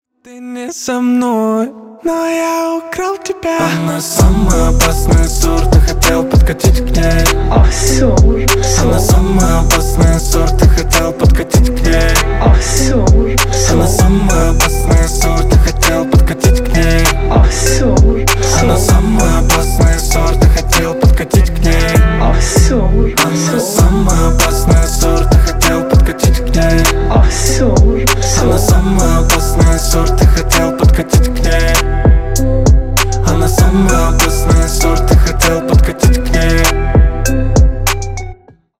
Рэп и Хип Хоп